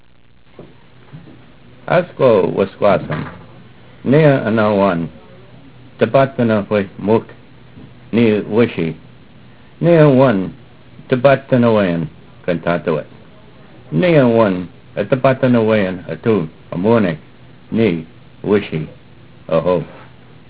Opening Prayer